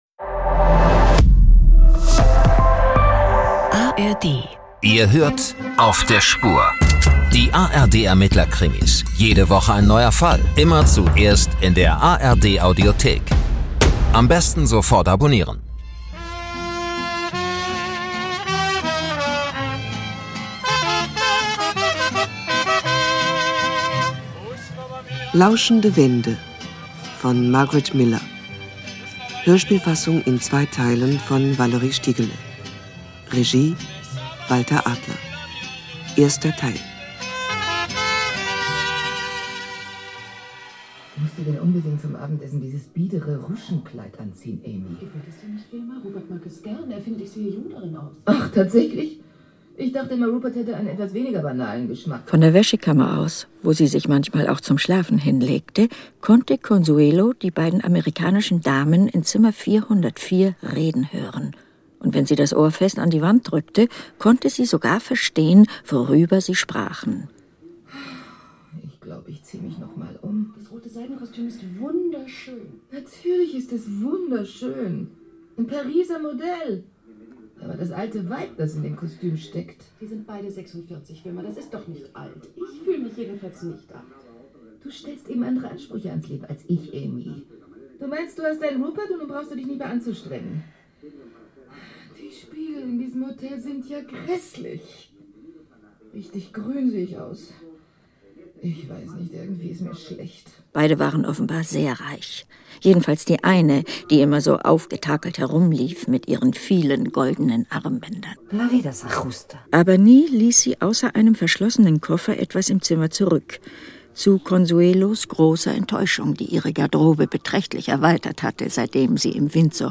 Erleben Sie eine tiefgründige Botschaft über die sichtbar bevorstehende Wiederkunft Jesu Christi, die grösste Hoffnung und das zentrale Ereignis der Menschheitsgeschichte.